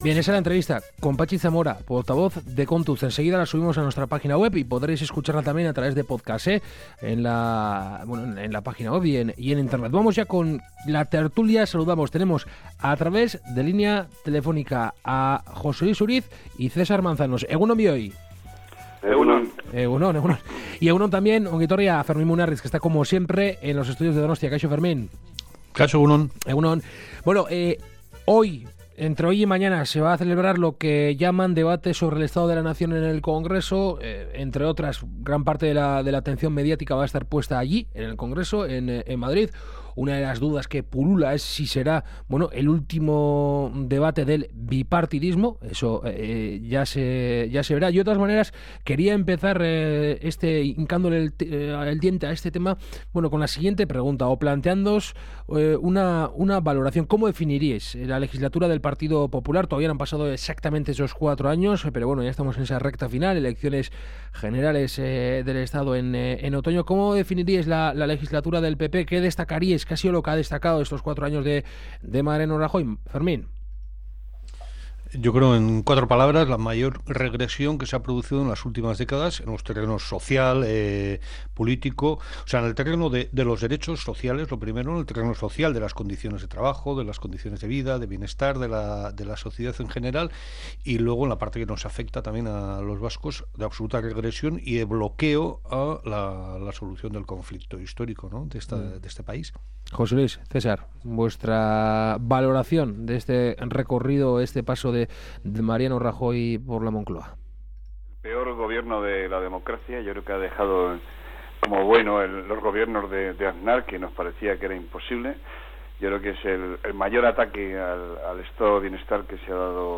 La tertulia de Kalegorrian: debate sobre el estado de la nación 2015, Grecia…
Charlamos y debatimos sobre algunas de las noticias mas comentadas de la semana con nuestros colaboradores habituales.